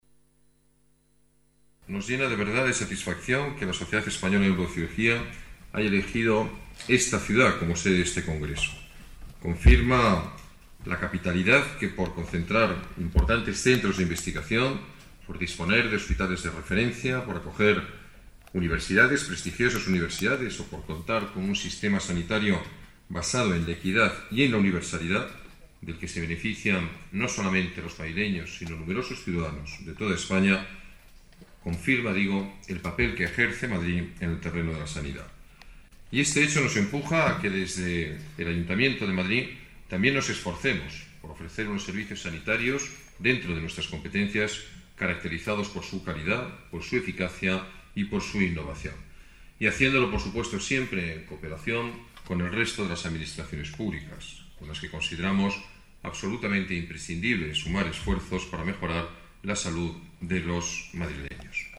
Nueva ventana:Declaraciones del alcalde en funciones, Alberto Ruiz-Gallardón: Congreso Neurocirugía